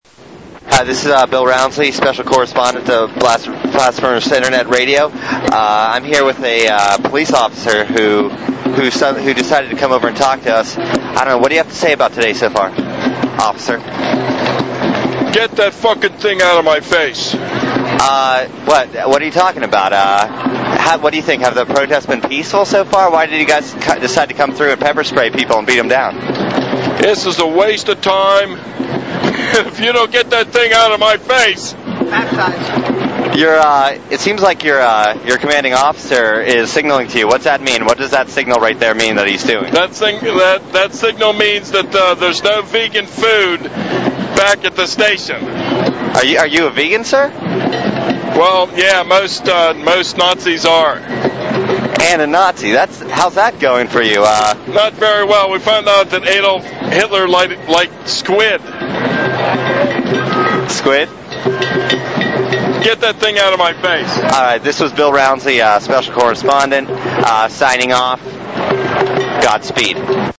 this clip (1:10, 556K) from a Blast Furnace Radio correspondent pretty much summarizes the balance of force - a cop drunk on power to the point of absurdity.